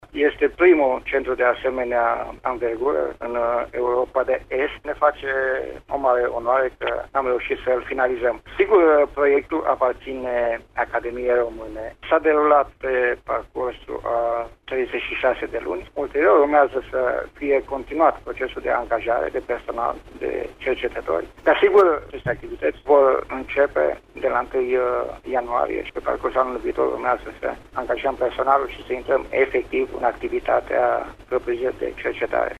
Într-o declaraţie acordată colegei noastre